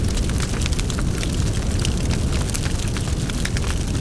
1 channel
Firebuild01.wav